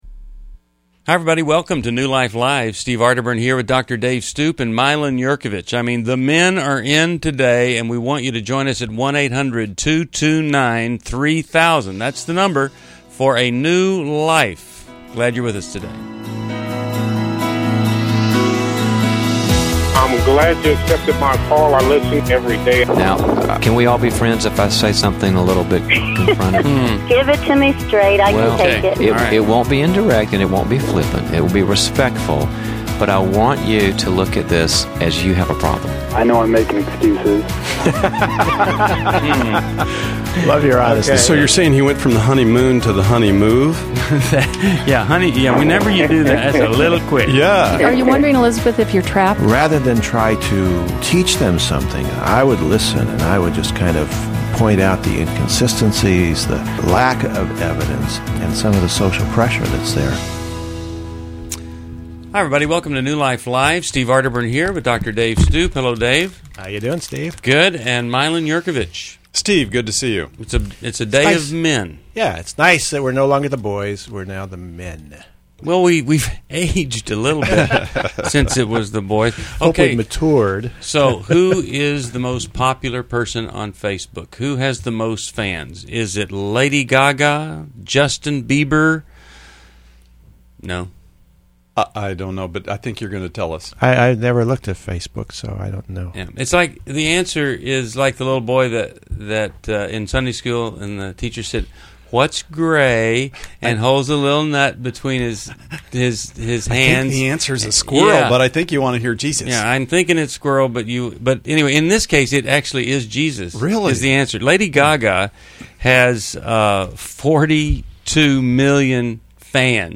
New Life Live: September 7, 2011 - The hosts tackle marriage prenups, infidelity, grief after loss, and dating fears, offering insights and support for listeners.
Caller Questions: 1.